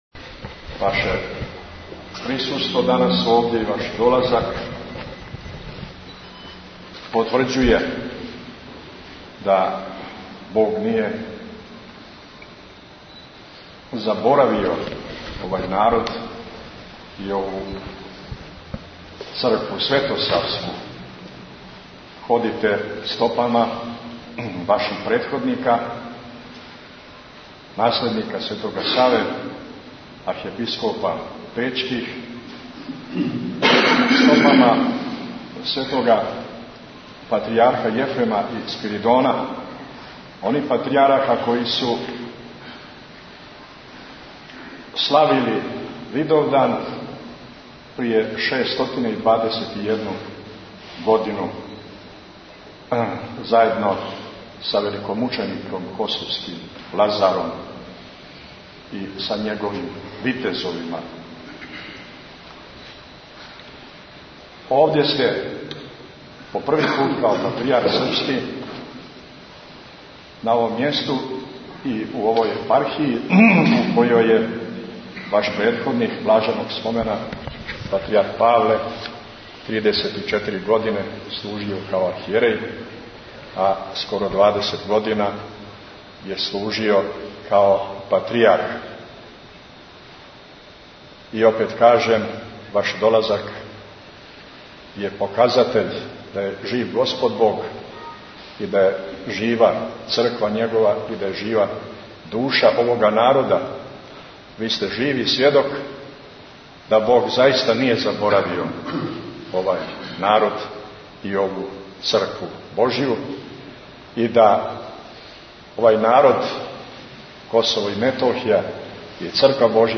У грачаничком храму је одслужена свечана докслологија после које се Митрополит Амфилохије обратио Његовој Светости пожелевши му добродошлицу на светолазаревско Косово и Метохију.